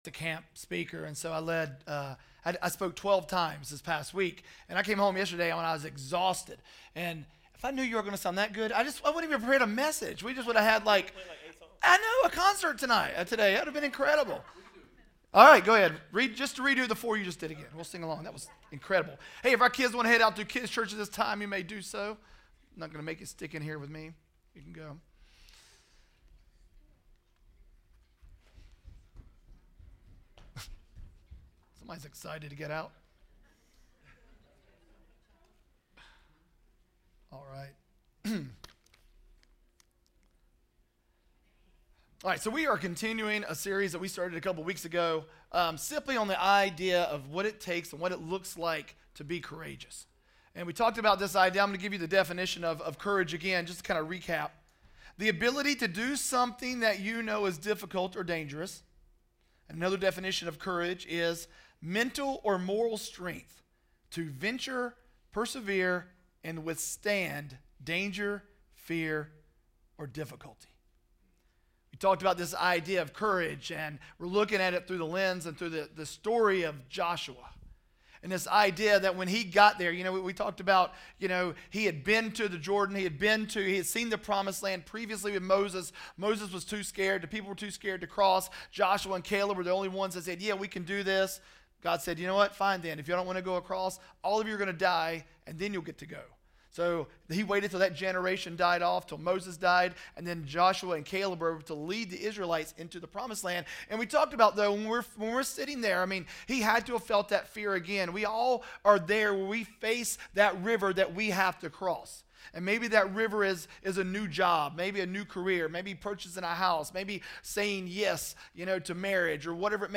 RE3 Sermon Audio | Project:Re3